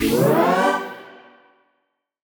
FR_T-PAD[up]-G.wav